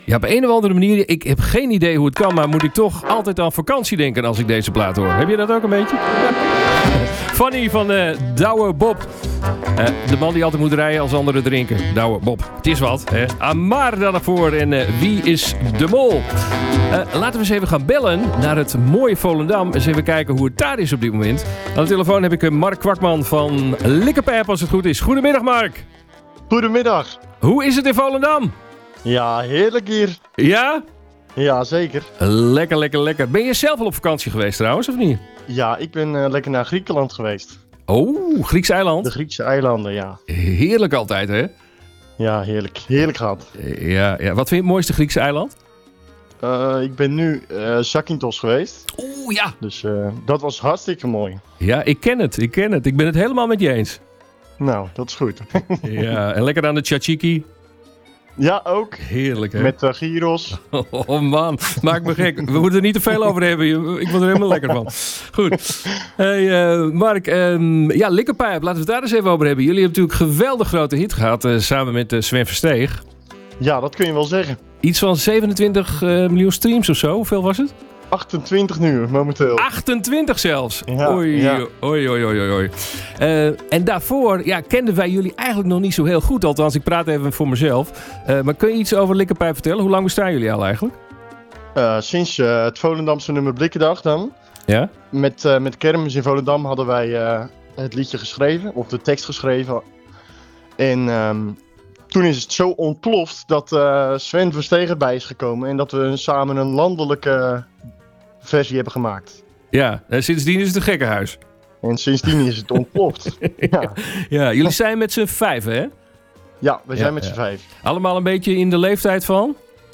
Ze hebben nu zelf de Radio Continu Schijf te pakken. Luister hier naar het gesprek.